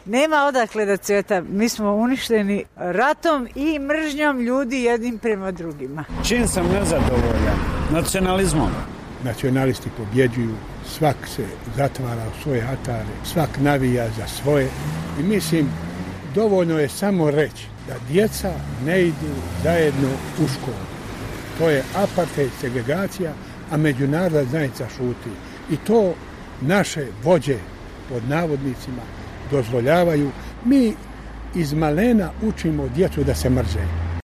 U Doboju i Mostaru to ovako komentarišu: